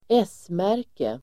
Uttal: [²'es:mär:ke]